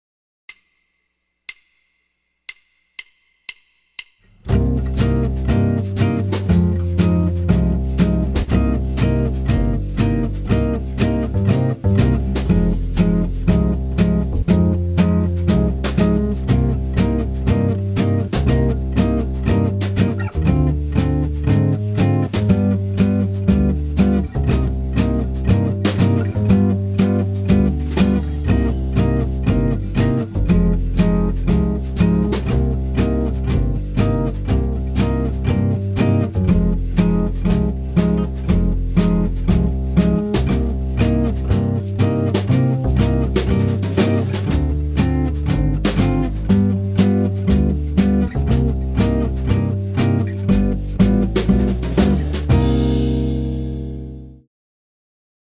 Minor Blues C Minor
09a-C-Minor-Blues-.mp3